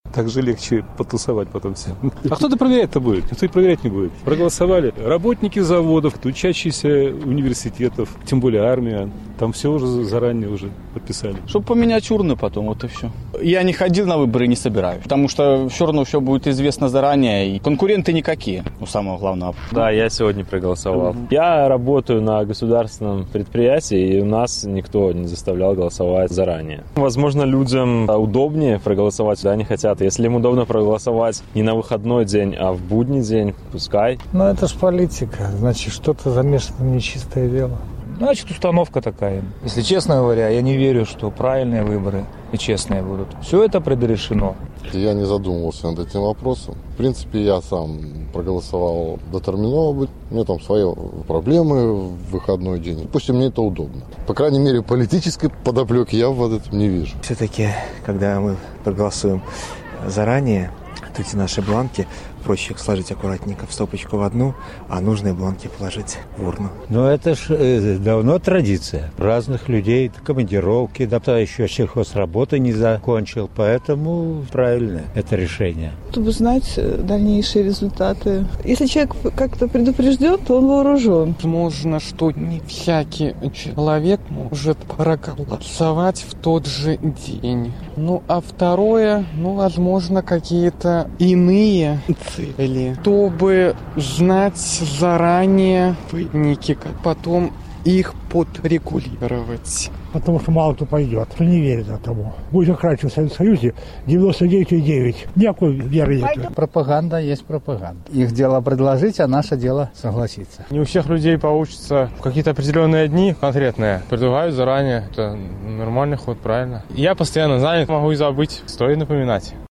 Адказвалі жыхары Магілёва.